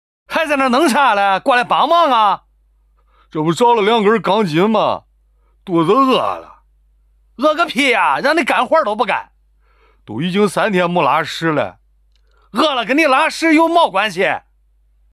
幽默角色--模仿名人